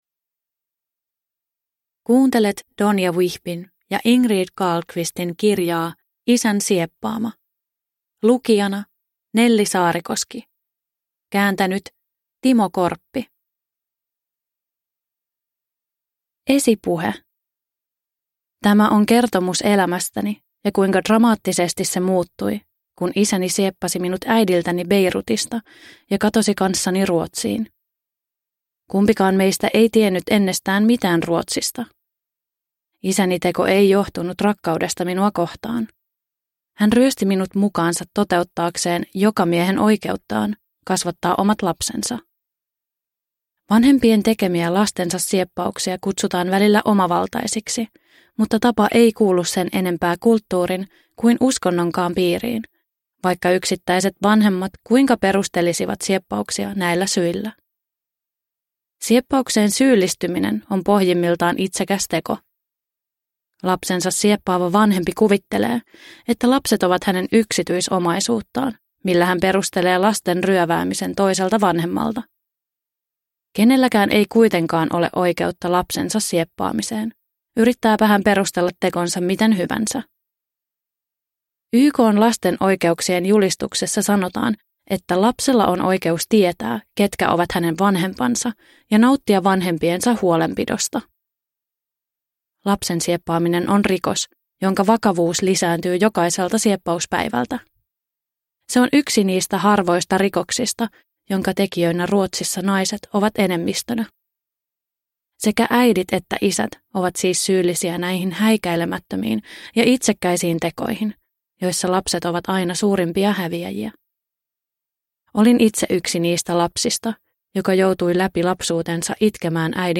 Isän sieppaama – Ljudbok – Laddas ner